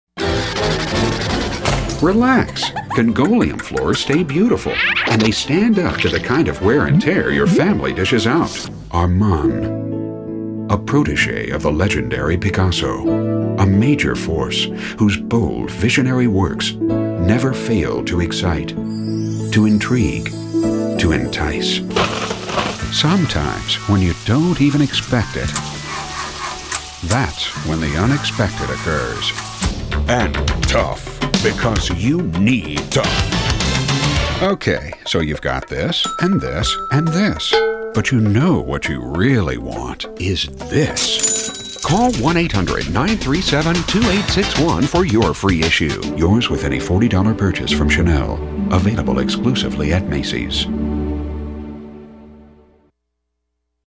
Benevolent, clean, polished, mature